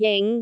speech
syllable
pronunciation
jing5.wav